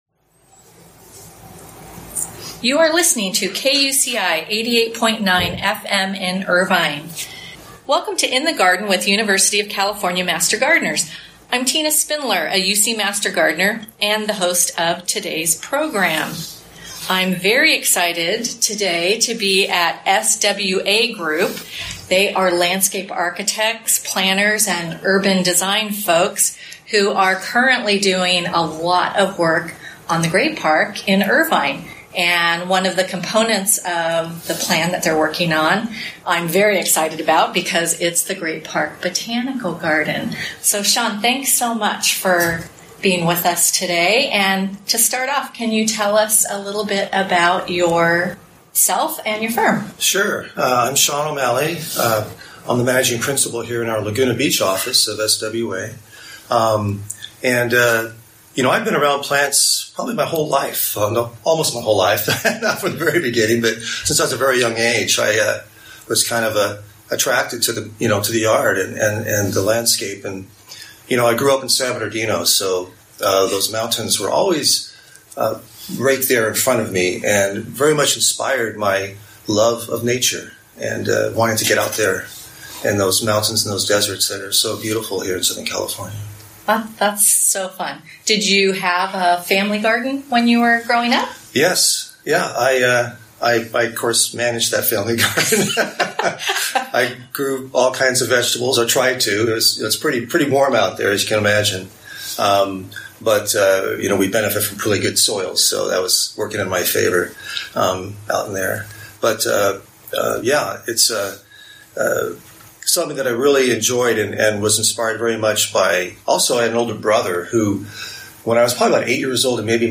This inspiring conversation aired on KUCI public radio (88.9 FM) and is now available as a podcast on the UC Master Gardeners website for those who missed the live broadcast.